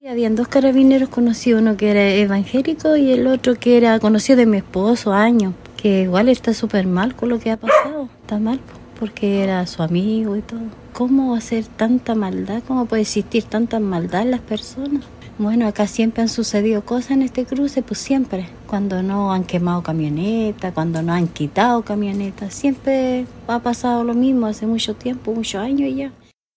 En un recorrido por las inmediaciones del lugar del hallazgo de los cuerpos, Radio Bío Bío conversó con los vecinos, con la gente, con una población que es también víctima del terrorismo. Con miedo, relataron la pena y el impacto que les produce el triple homicidio de los carabineros.
vecina-canete.mp3